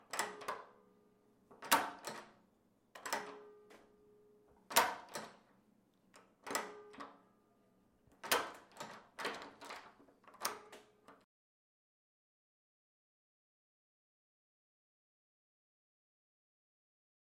Ulocking and locking doors BMW 118i
描述：The unlocking and locking of a BMW 118i's doors: loud knocks as it unlocks and locks.
标签： Interior Vehicle BMW Unlock Locking Car OWI
声道立体声